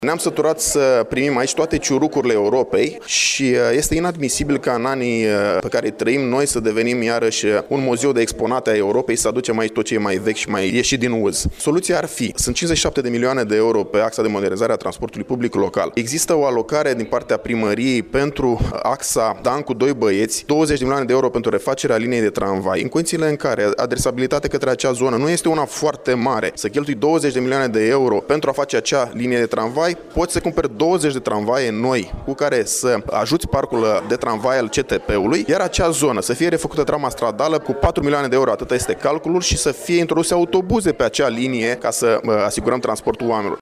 Primăria Iaşi poate accesa fonduri europene prin intermediul axei de modernizare a transportului public local în vederea achiziţionării de tramvaie noi, a afirmat, astăzi, într-o conferinţă de presă deputatul PNL, Marius Bodea, preşedinte al filialei municipale Iaşi a partidului.
Deputatul PNL de Iaşi, Marius Bodea: